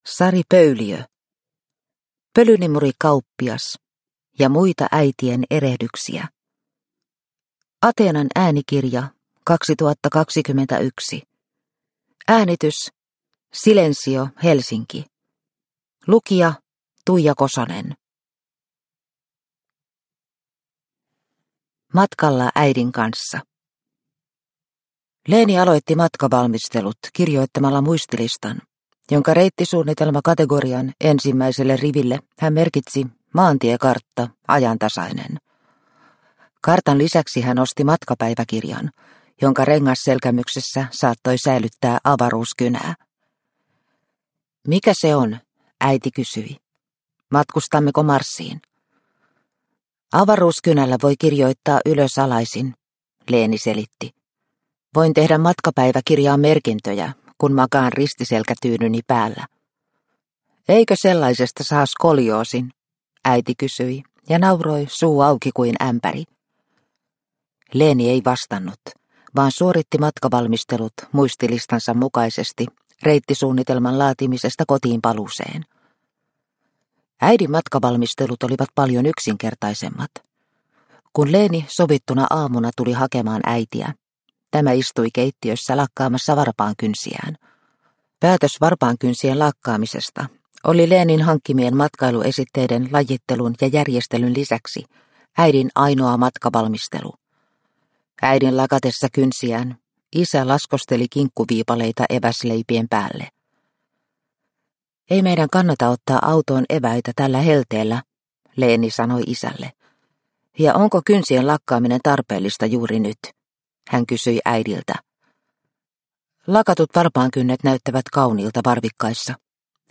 Pölynimurikauppias – Ljudbok